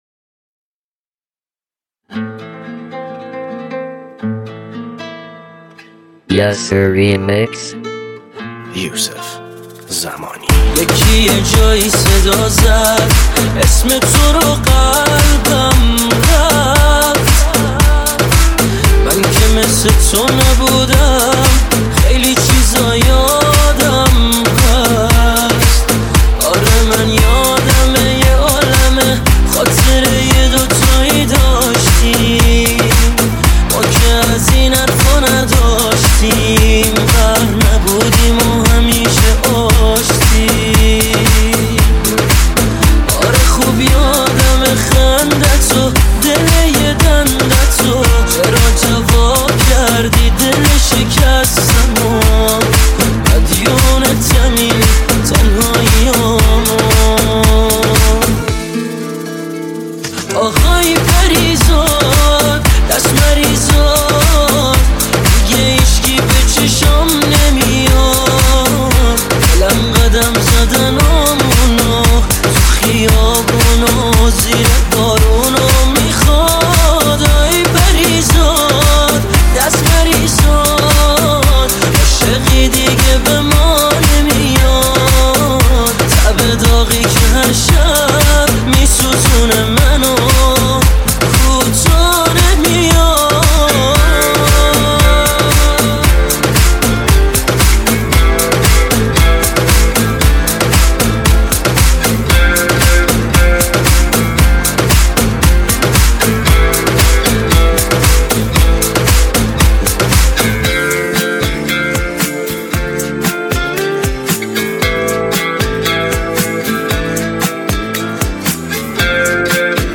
New Remix